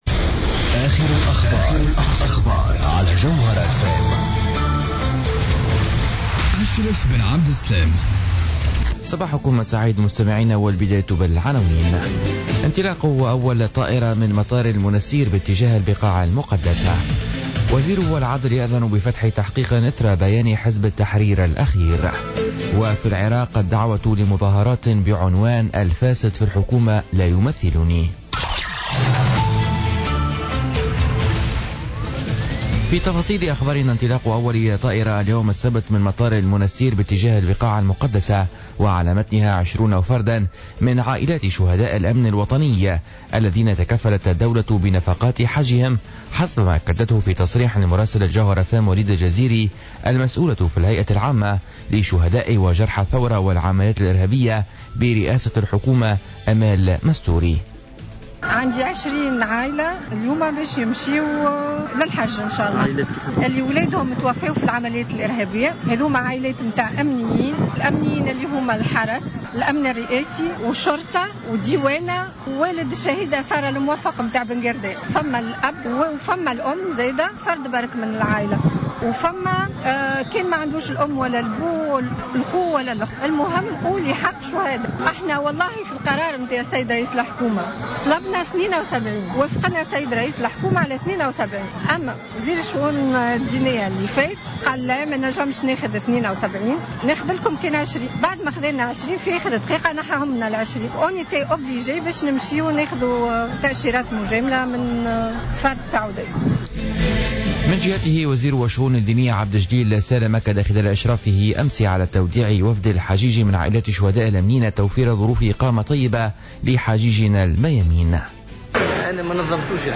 نشرة أخبار السابعة صباحا ليوم السبت 3 سبتمبر 2016